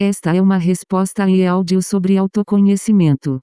tmp_audio_tts.aiff